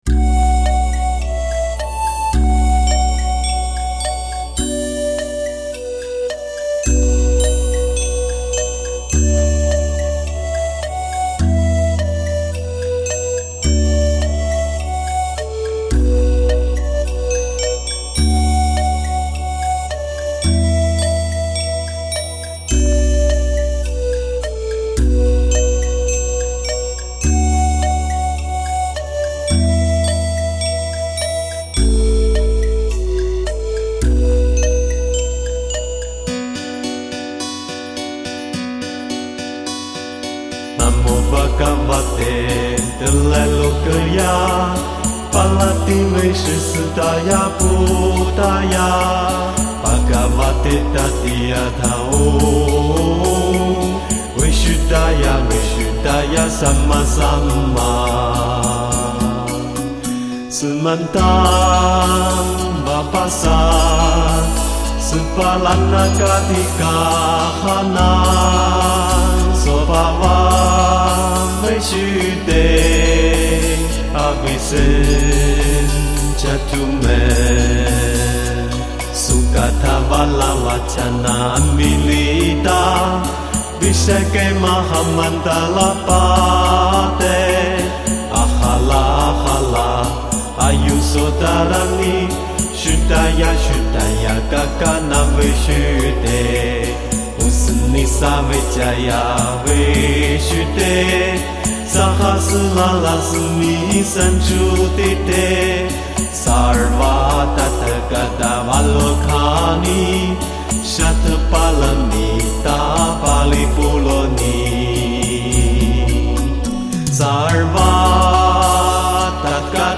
取消所有重复，一遍接着一遍的唱诵，个人觉得衔接的比较流畅，比较完美！